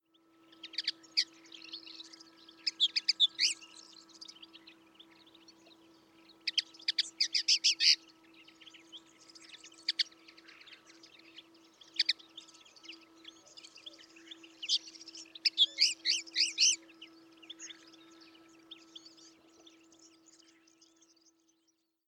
Fanello
• (Linaria cannabina)